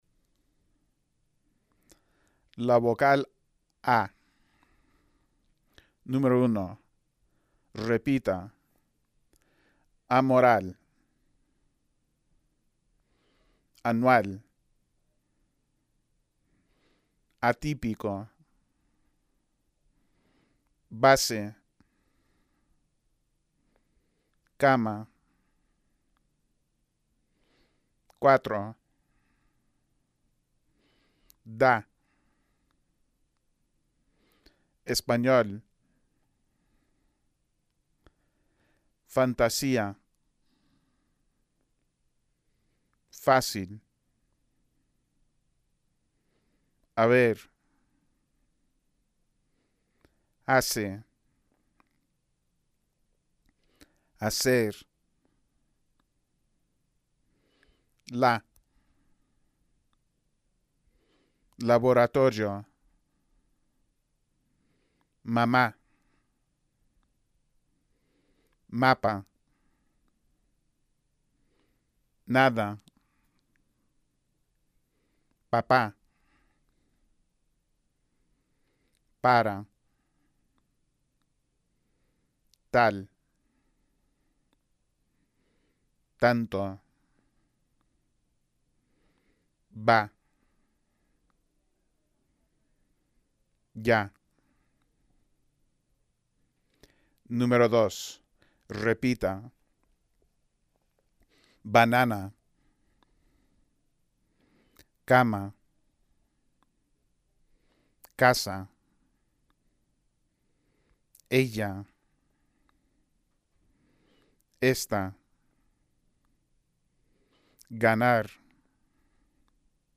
Ejercicios de pronunciación: la vocal /a/.